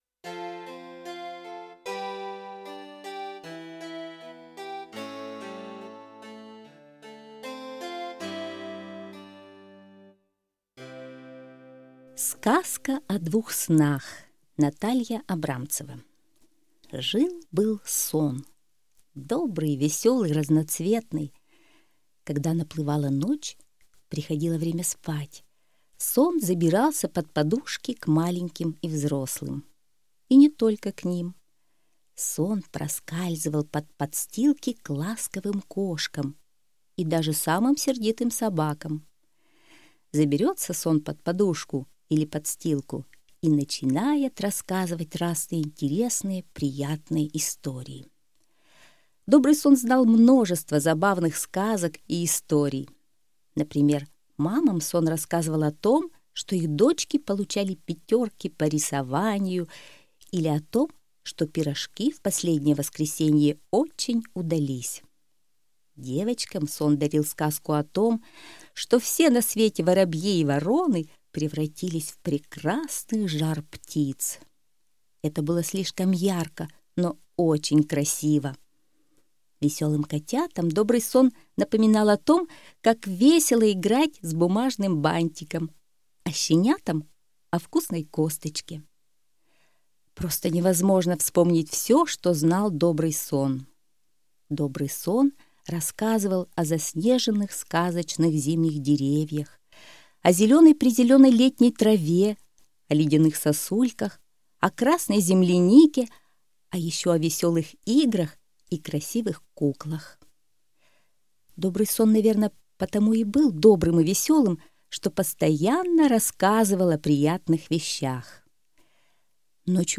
Слушайте Сказка о двух снах - аудиосказка Абрамцевой Н. История о двух снах: веселом и страшном.